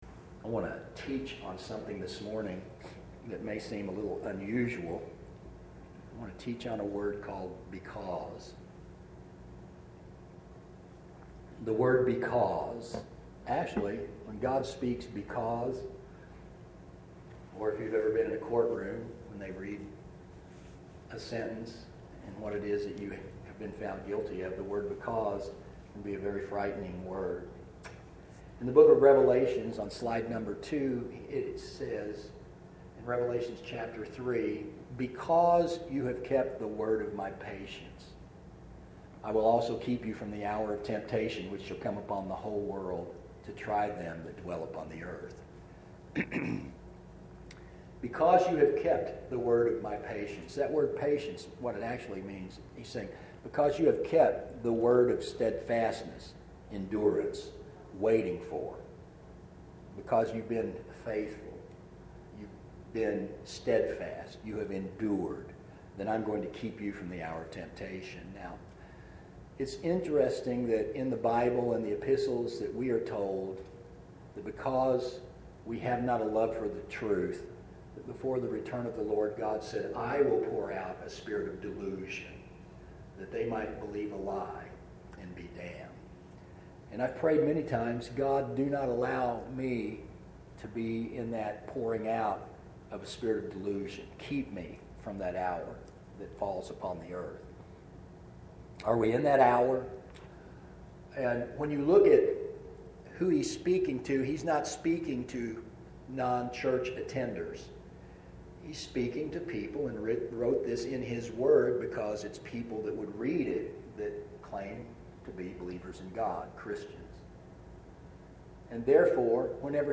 A sermon about a word used in scripture that when used can have great consequences. Generally speaking the word because is used following certain actions. Who is in charge of the because?